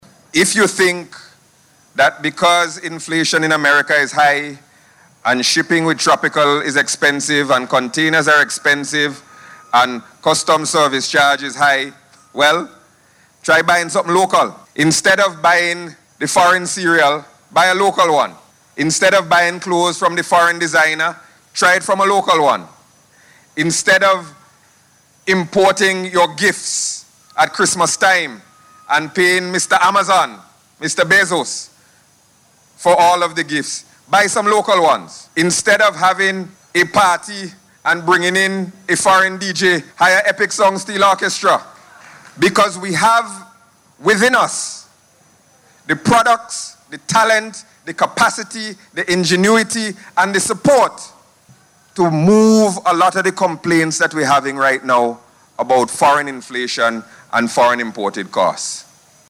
Minister of Finance and Economic Planning Camillo Gonsalves made the appeal, as he addressed the official opening of the 2022 Everything Vincy Expo, at the Geest Terminal in Kingstown yesterday.